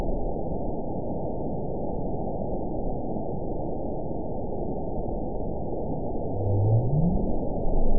event 920458 date 03/26/24 time 18:23:53 GMT (1 year, 1 month ago) score 9.48 location TSS-AB01 detected by nrw target species NRW annotations +NRW Spectrogram: Frequency (kHz) vs. Time (s) audio not available .wav